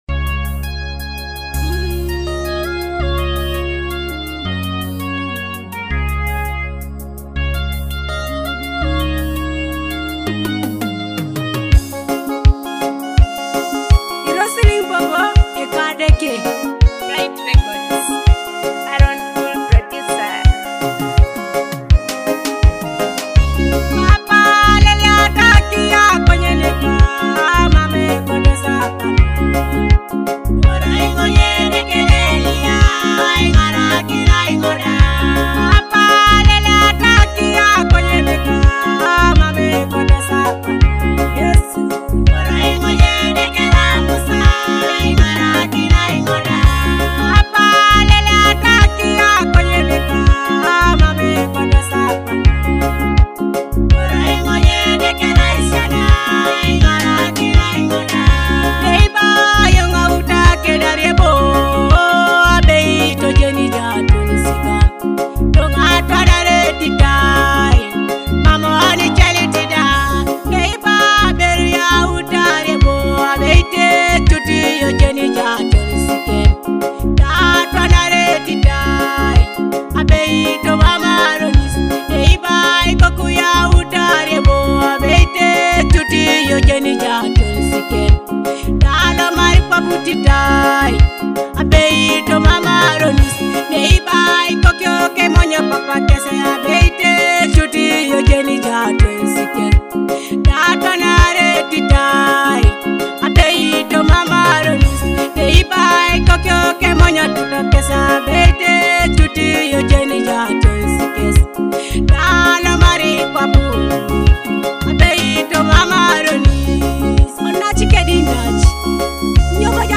Teso gospel music